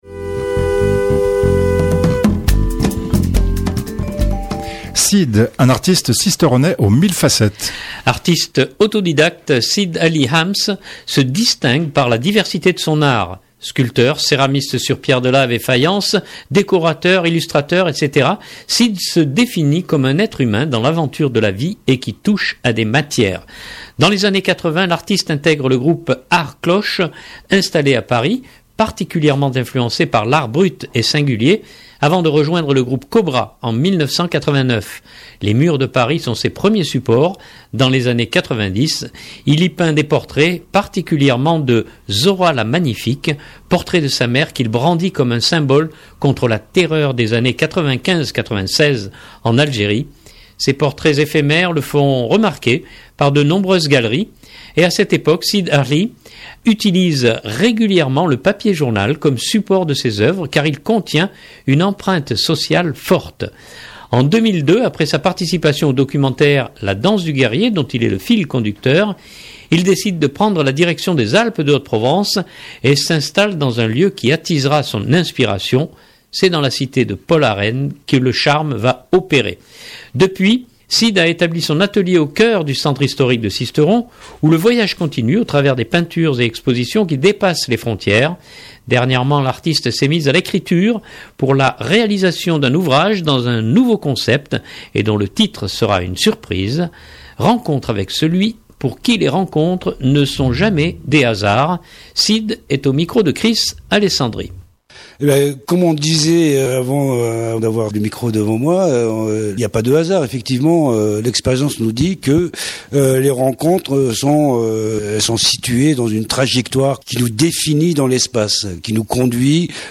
Dernièrement l’artiste s’est mis à l’écriture pour la réalisation d’un ouvrage dans un nouveau concept et dont le titre sera une surprise… Rencontre avec celui pour qui les rencontres ne sont jamais des hasards !